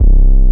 59 BASS 1 -R.wav